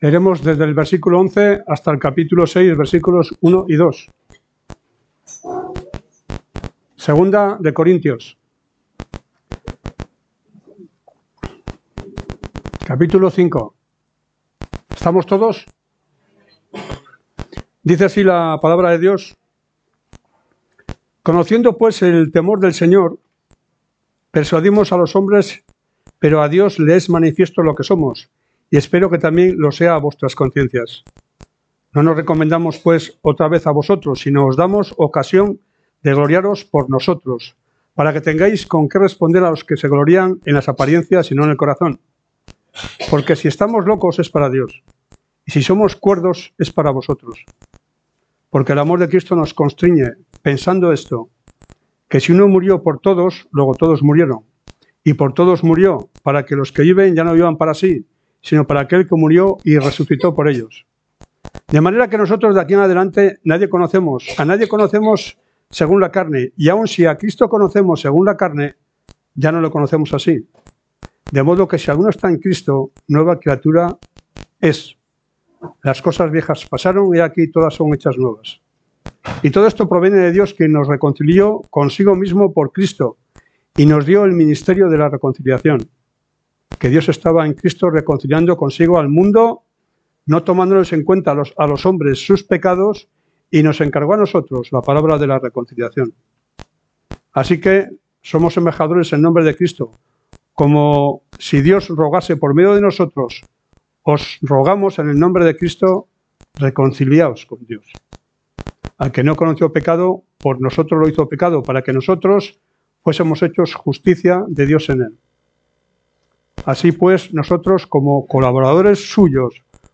Predicación